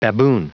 Prononciation du mot baboon en anglais (fichier audio)
Prononciation du mot : baboon